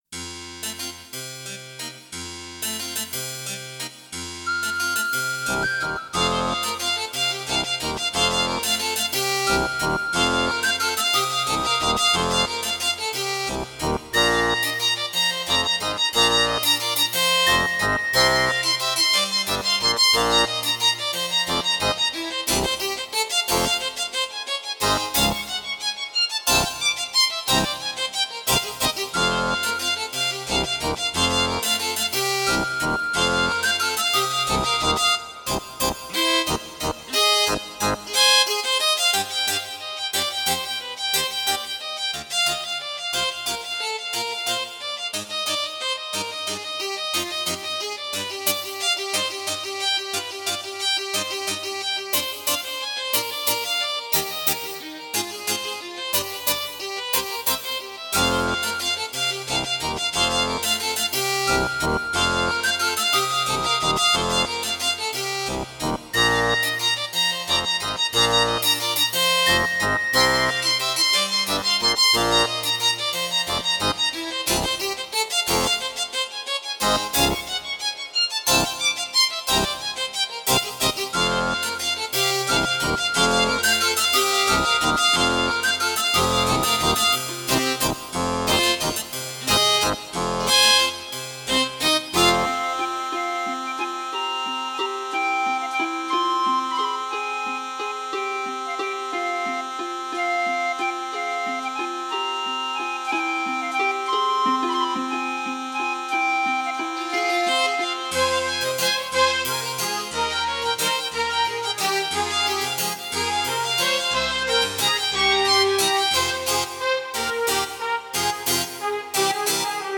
Alleluia! Sing to Jesus [Jig]